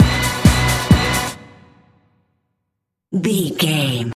Fast paced
Ionian/Major
Fast
synthesiser
drum machine
Eurodance